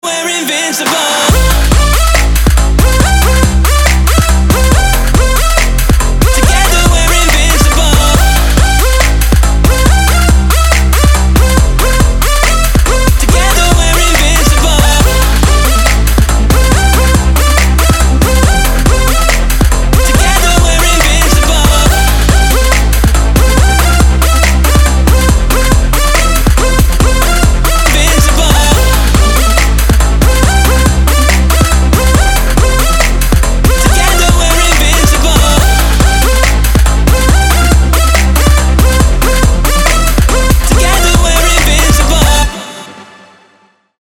DubStep
Метки: Dubstep, Trap, future bass,